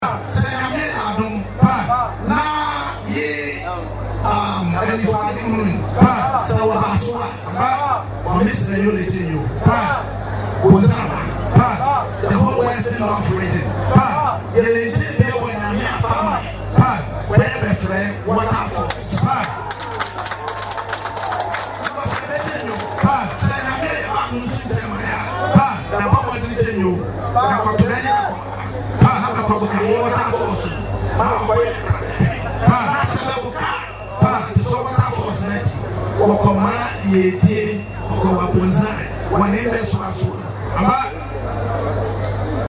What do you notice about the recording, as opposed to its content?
during the climax of the 2024 Elluo Festival.